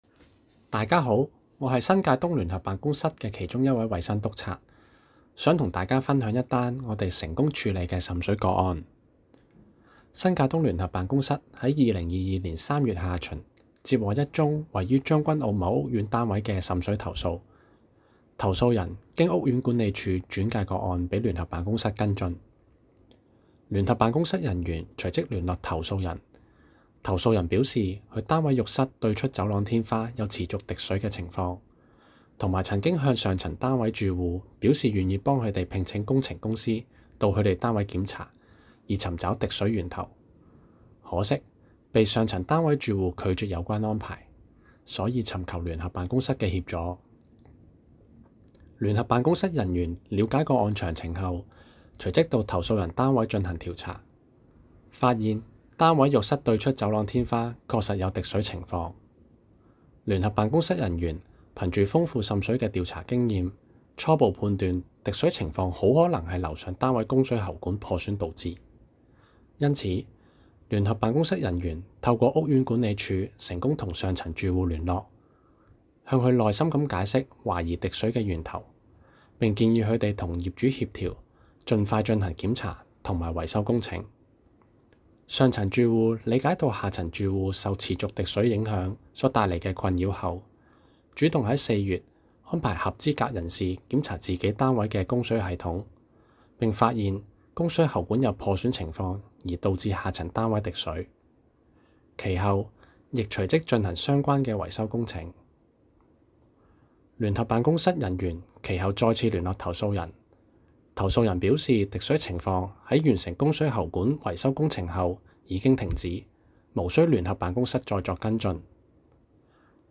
Case No. Year (Region) The voice of Joint Office investigating officer